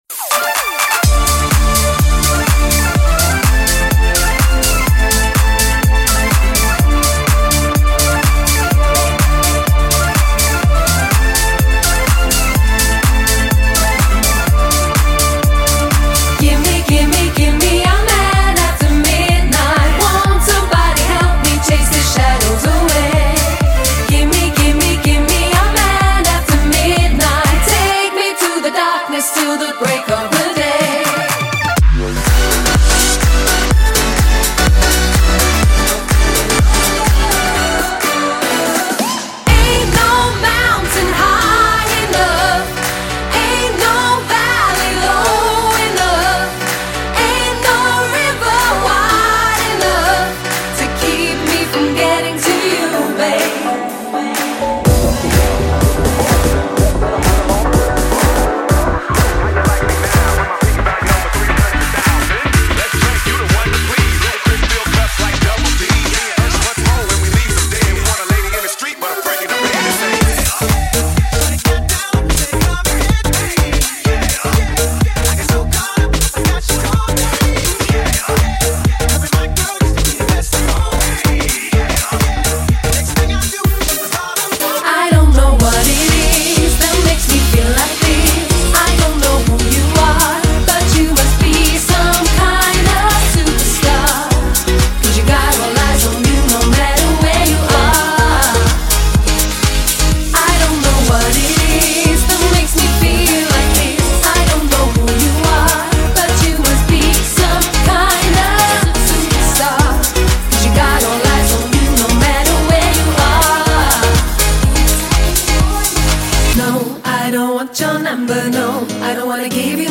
Highly engaging and energetic female DJ and vocalist.
• Unique DJ live experience featuring female vocals
DJ/Vocals